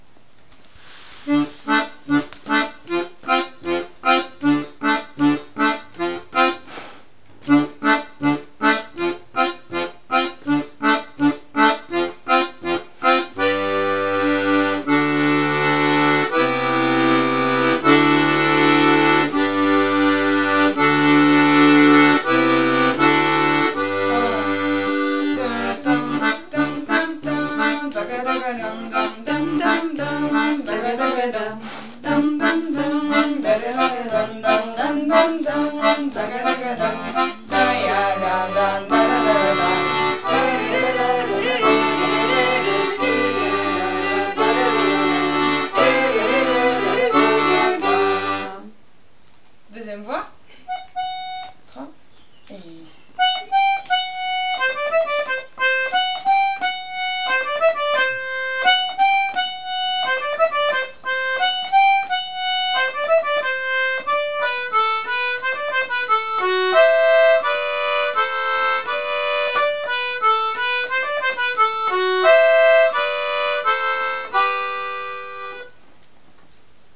l'atelier d'accordéon diatonique
Taïtou (polka)
accompagnement puis 2ème voix
taitou accompagnement  et 2eme voix.mp3